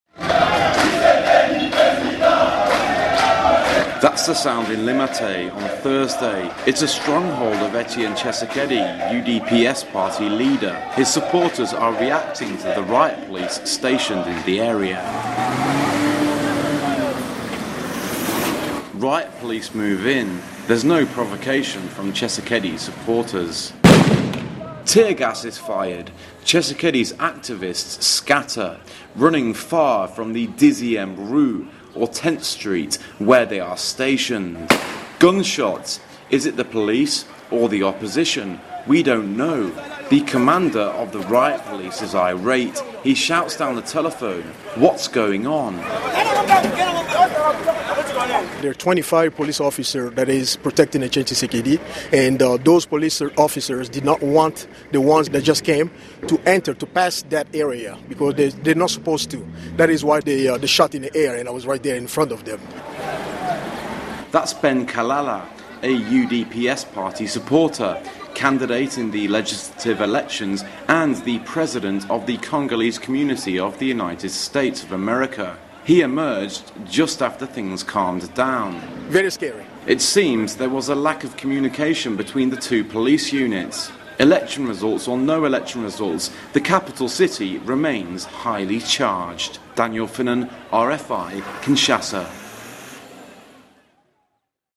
Report: Clashes in Limete, Kinshasa